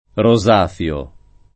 [ ro @# f L o ]